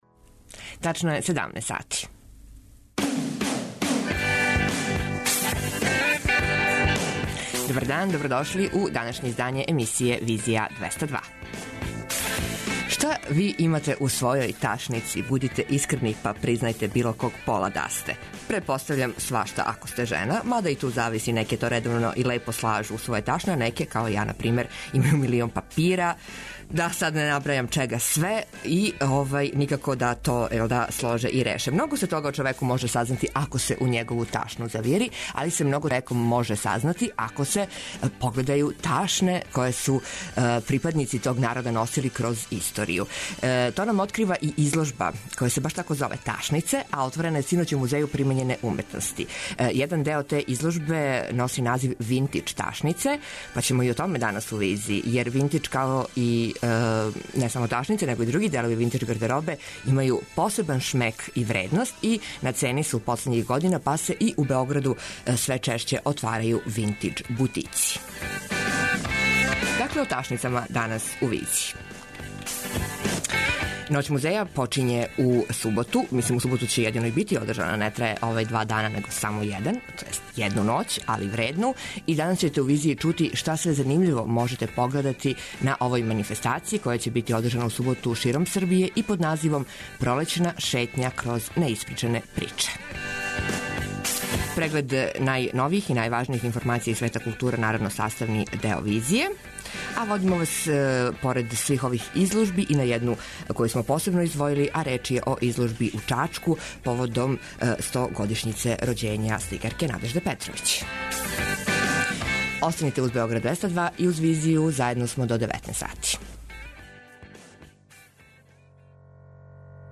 преузми : 55.69 MB Визија Autor: Београд 202 Социо-културолошки магазин, који прати савремене друштвене феномене.